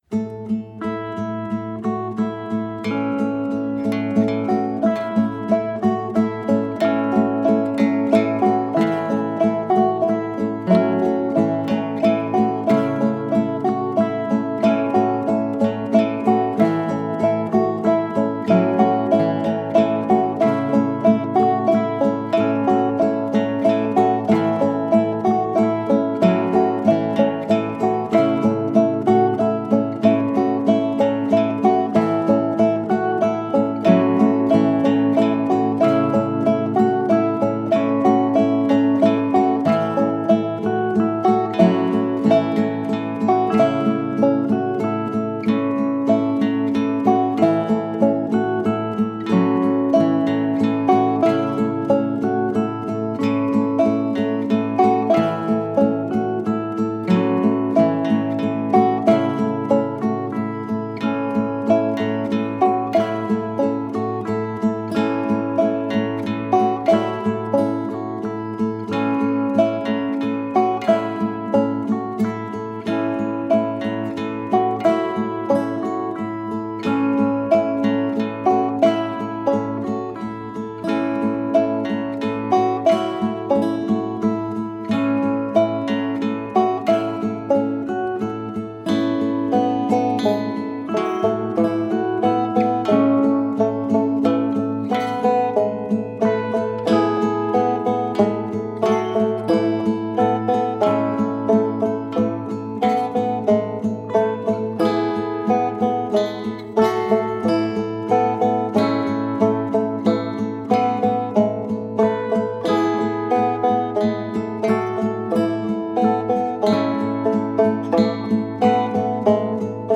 (drone)